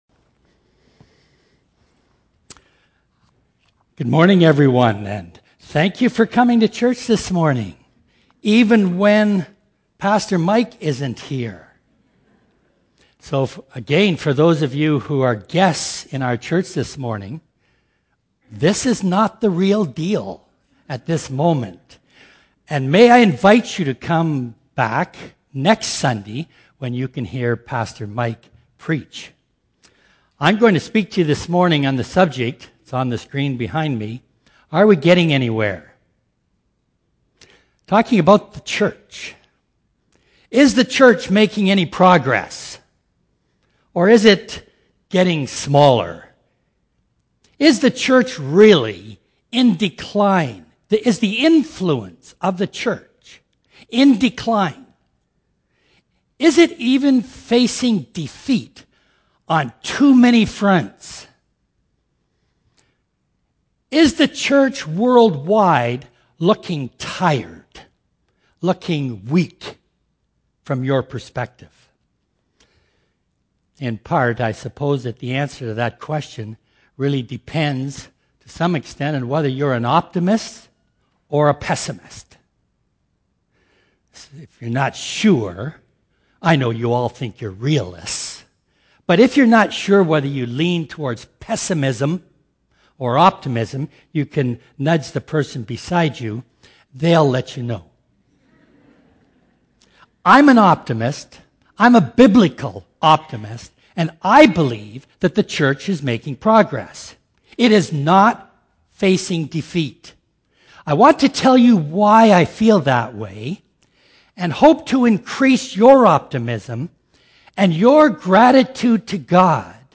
Sermon Archives - West London Alliance Church
West London Alliance Church is a church dedicated to making known the greatness of God in the city of London, Ontario.